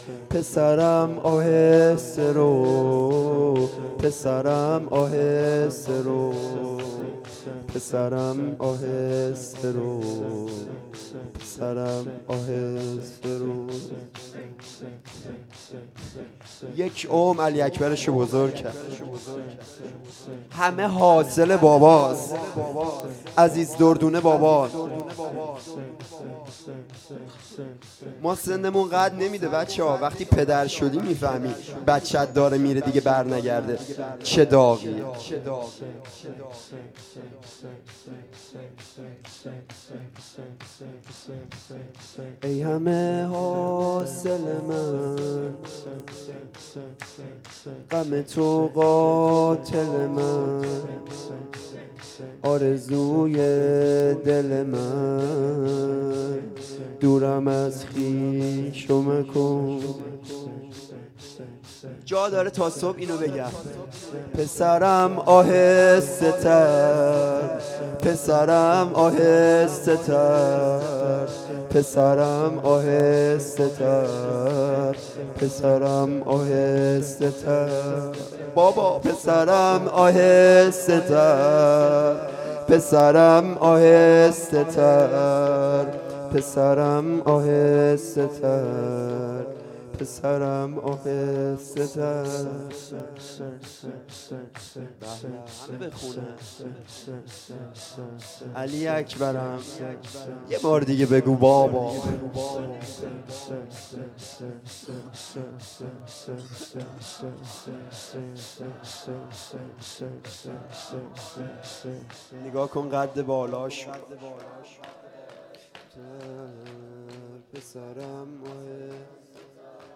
سه ضرب
شب هشتم ماه محرم